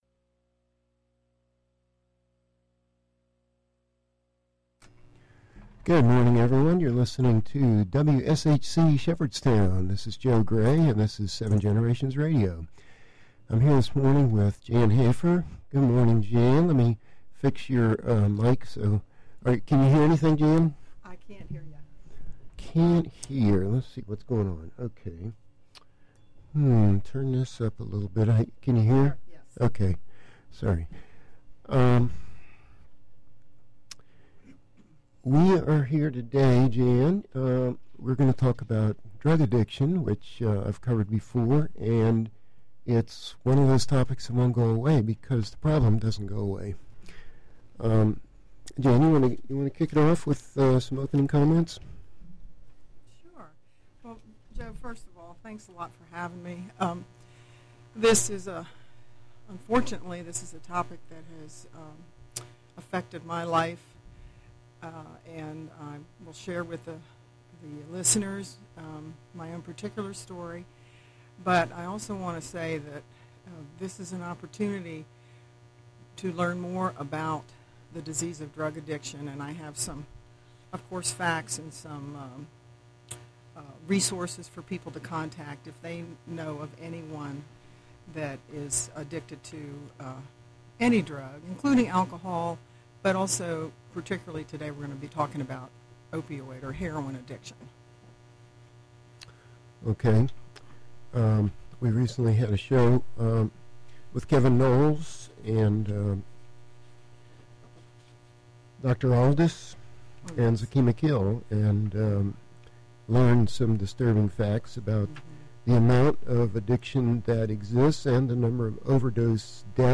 Originally broadcast on WSHC Shepherdstown, from the campus of Shepherd University on April 30, 2016.